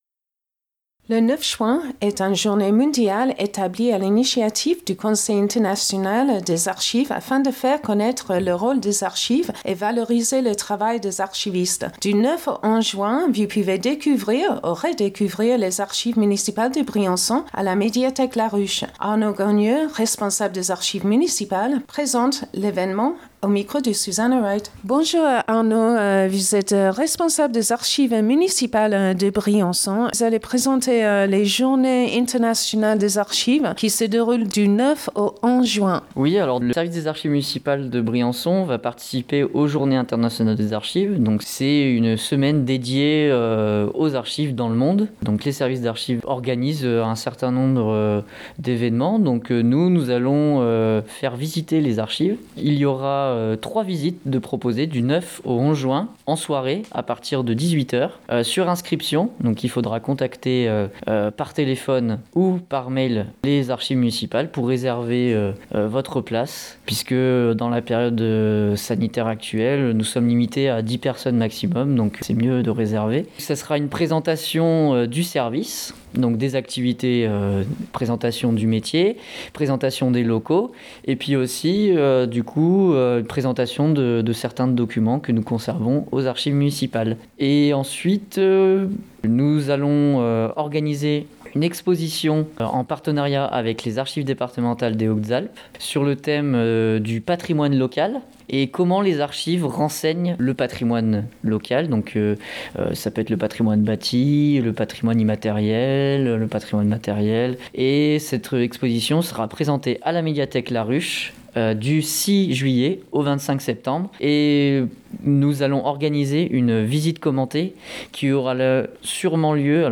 présente l’événement au micro